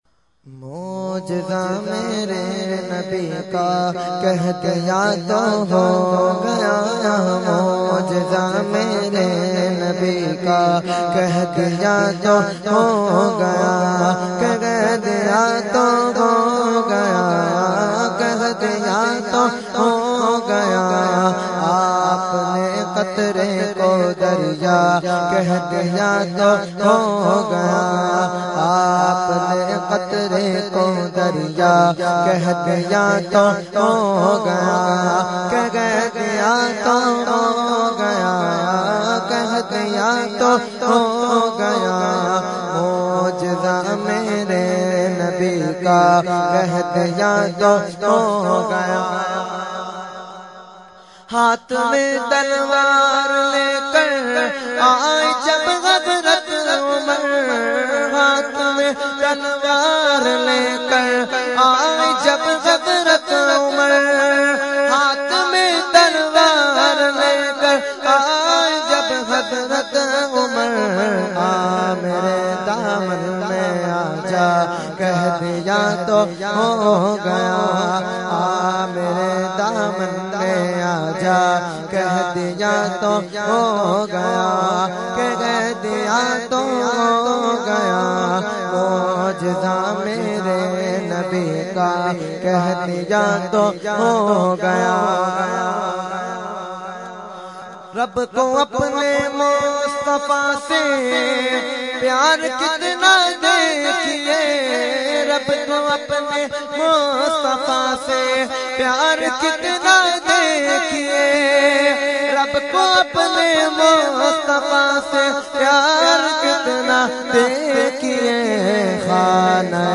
Category : Naat | Language : UrduEvent : 11veen Sharif Lali Qila Lawn 2015